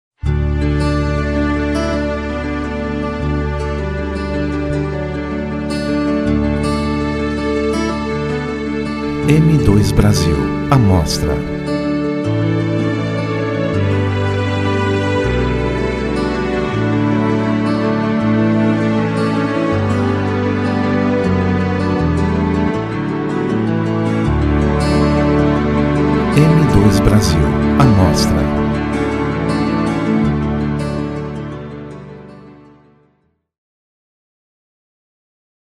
Músicas de Fundo para URA